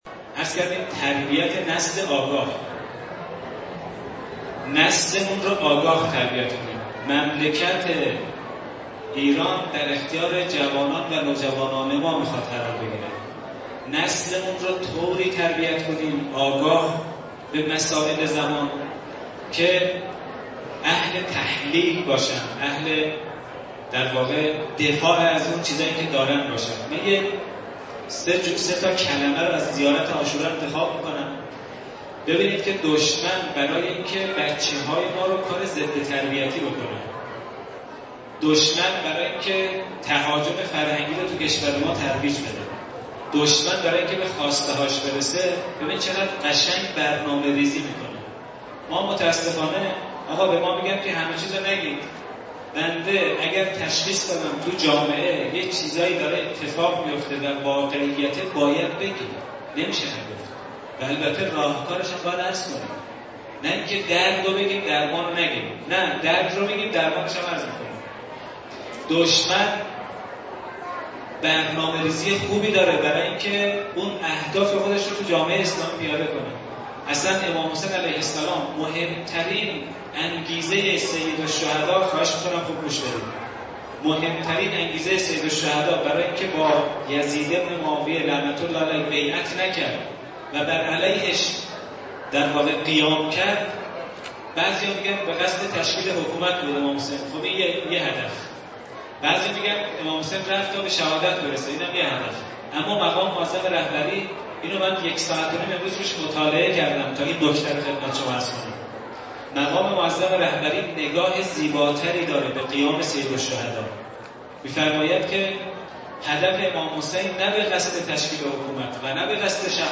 مراسم ویژه شب شام غریبان حسینی در سقاخانه حضرت اباالفضل (ع) گلپایگان با حضور عاشقان حضرت اباعبدلله الحسین (ع) برگزار گردید.
سخنرانی و روضه خوانی